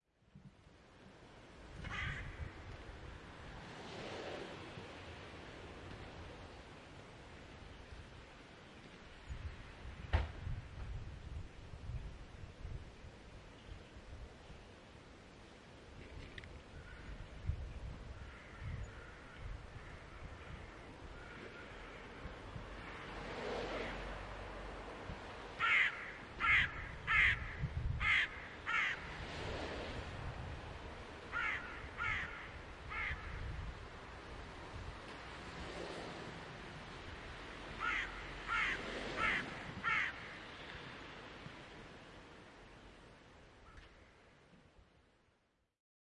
乌鸦
描述：在德国伊尔默瑙湖附近的乌鸦现场记录。记录设备：放大HN2记录技术：M / S
Tag: 现场记录 自然